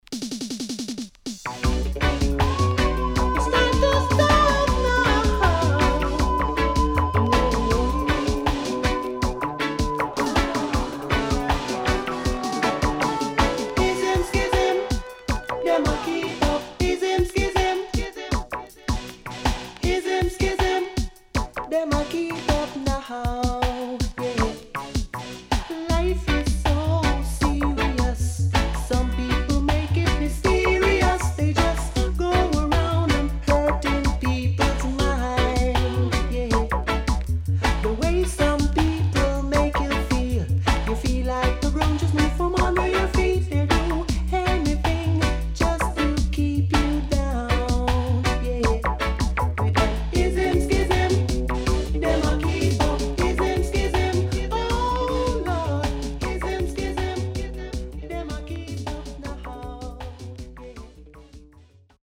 SIDE A:所々チリノイズ入りますが良好です。